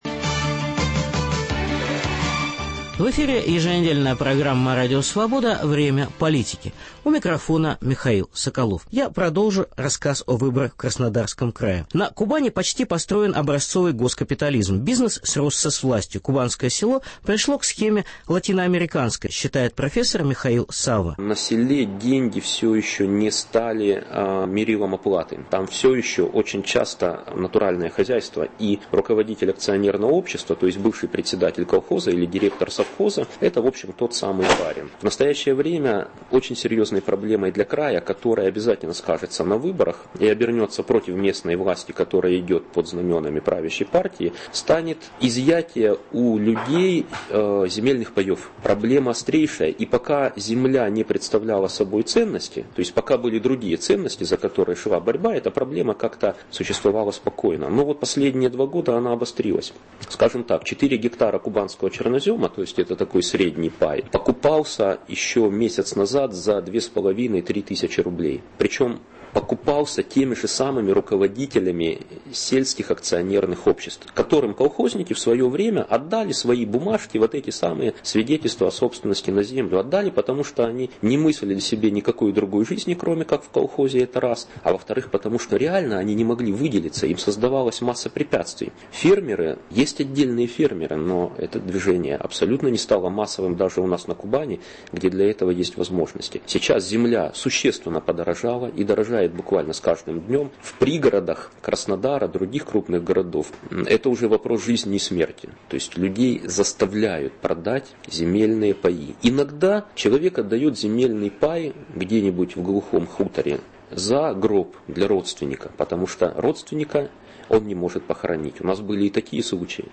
Специальный репортаж о выборах в Краснодарском крае.(продолжение)